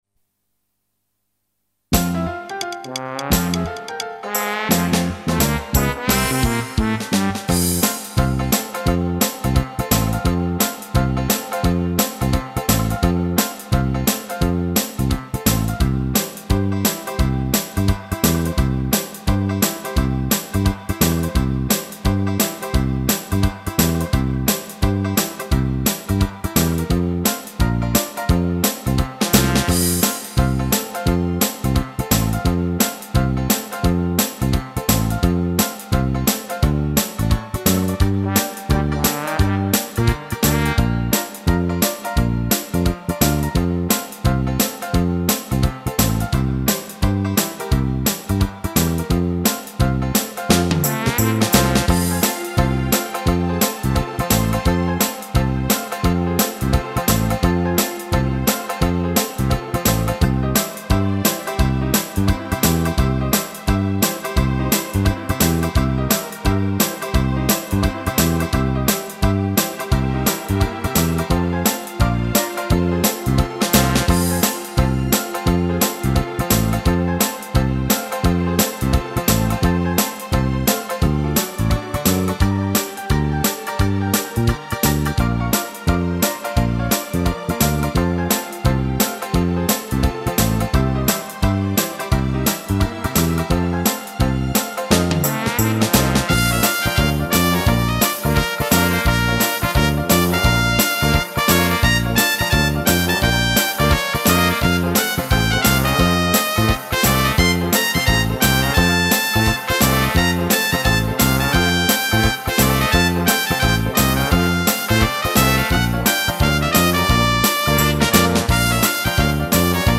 Fox-Steep   Vocale